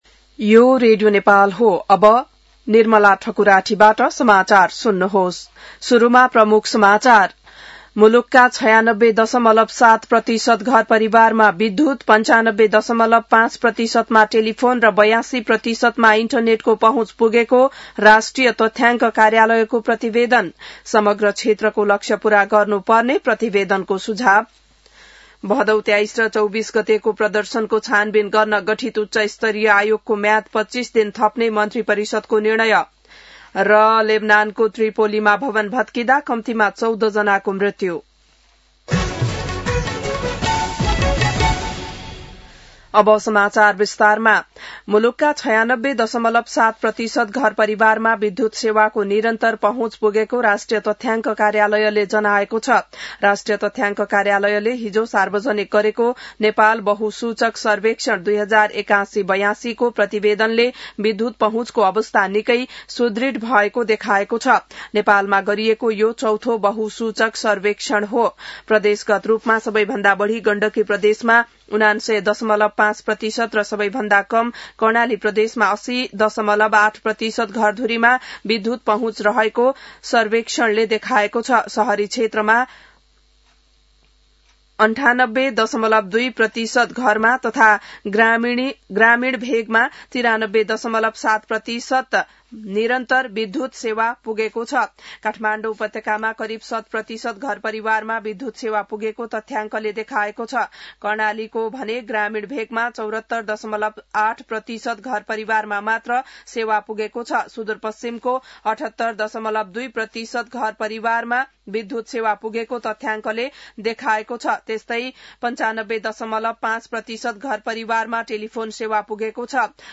बिहान ९ बजेको नेपाली समाचार : २७ माघ , २०८२